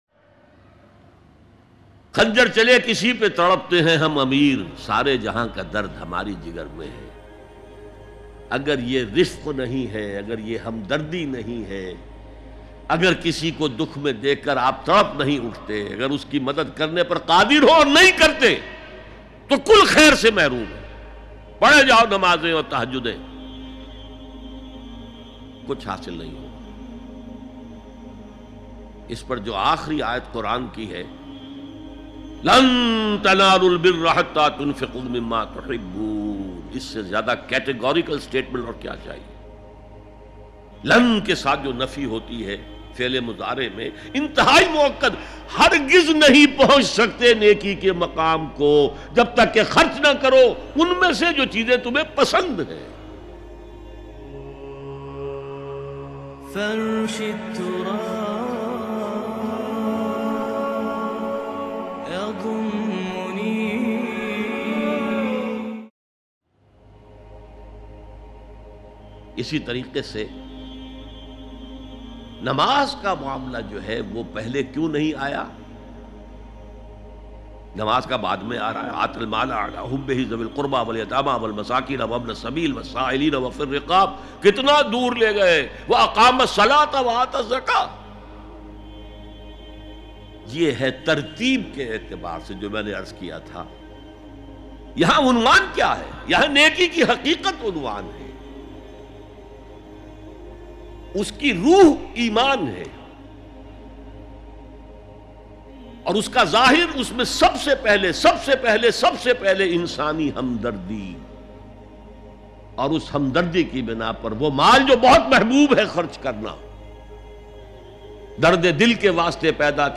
Asal Neki Kya Hai Bayan MP3 Download By Dr Israr Ahmed